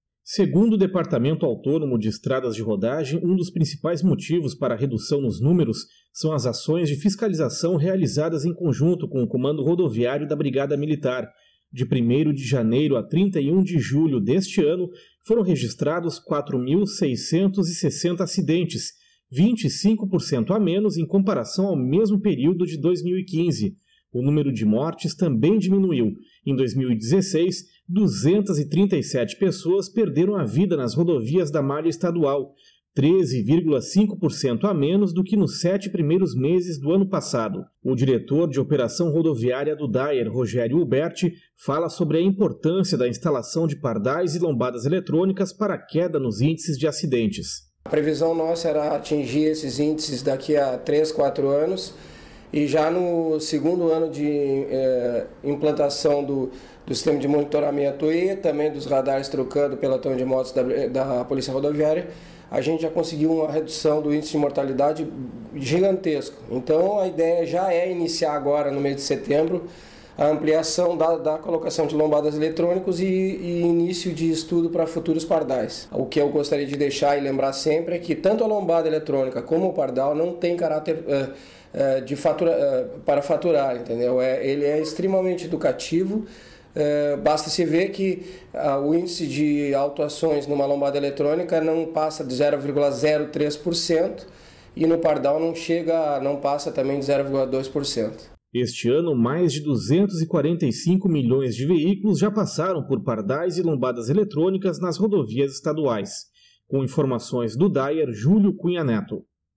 Boletim: Menos acidentes nas estradas estaduais